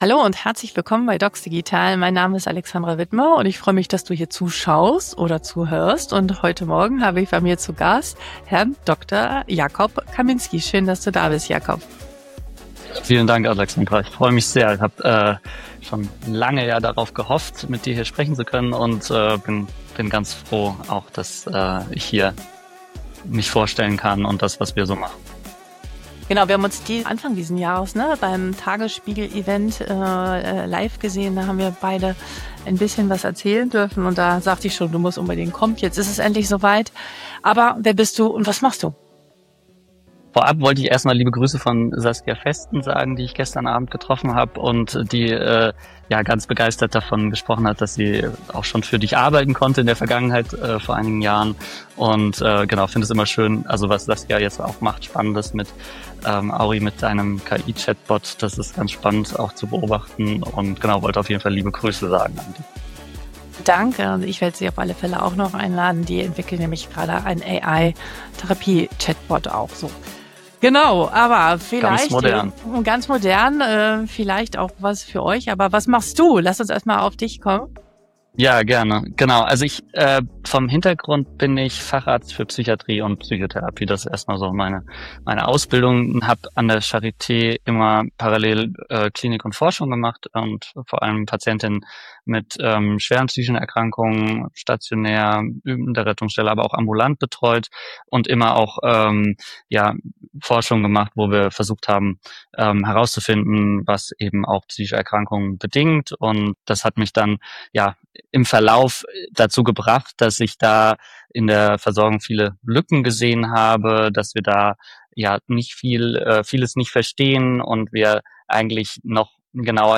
Ein Gespräch über klinische Realität, Shared Decision Making und den Mut, Versorgung neu zu denken.